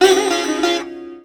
SITAR GRV 09.wav